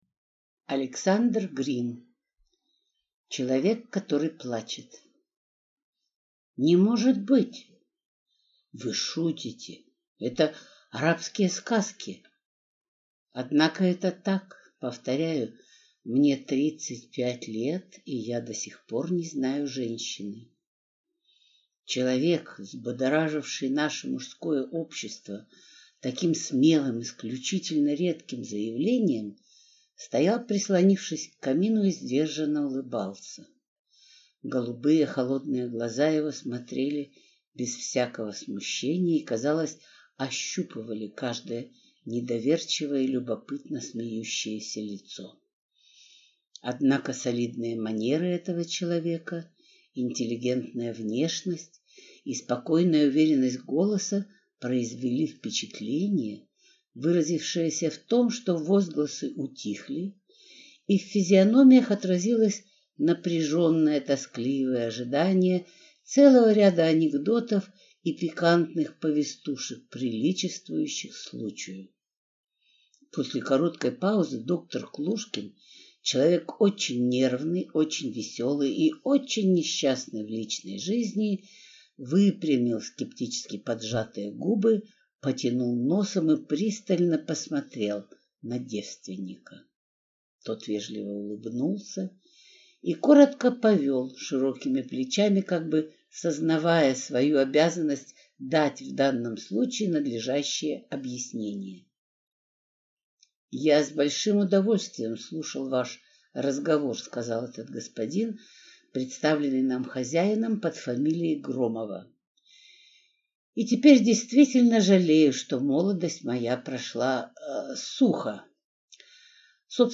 Аудиокнига Человек, который плачет | Библиотека аудиокниг